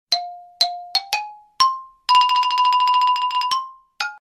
Kategorien Alarm